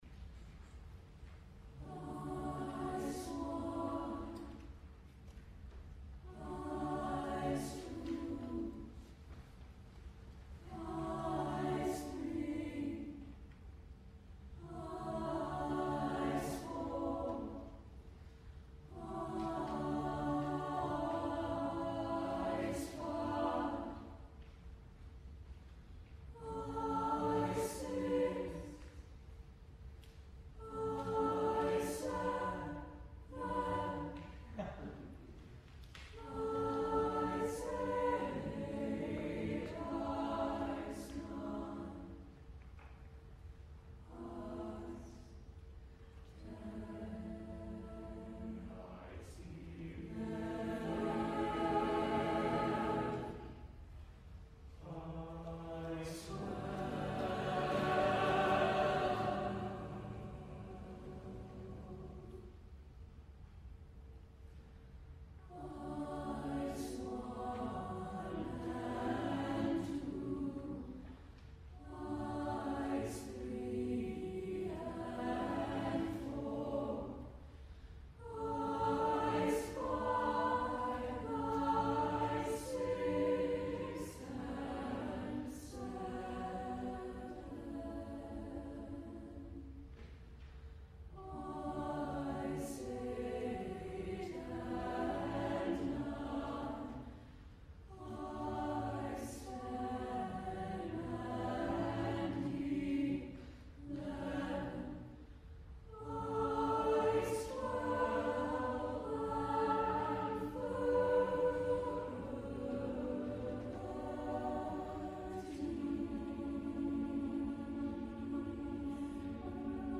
As far as the music itself, I wanted to write something quiet and introverted, with a spiritual quality.
"Ice Seventeen" concert recording